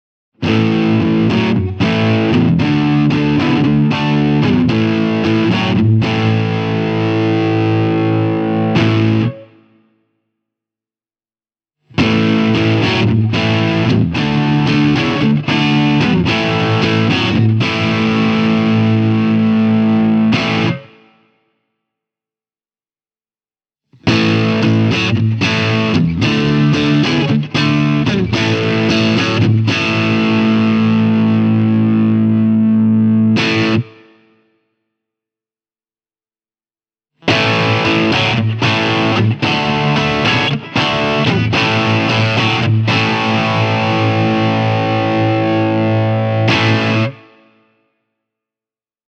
Testiyksilöä on lisäksi kustomoitu testiä varten erittäin laadukkailla Seymour Duncan Antiquity -mikrofoneilla.
Antiquity-mikeillä kitara soi heleästi, mutta paljon lämpimämmin kuin esim. Strato-tyylinen kitara. Komppi-piiri leikkaa tahallaan kaulamikrofonin signaalista hieman diskanttia, mutta ilman että soundi muuttuisi mutaiseksi.
Tältä kuulostaa Tokai TJM-140 Bluetone Shadows Jr. -kombon ja Boss SD-1 -särön kautta soitettuna: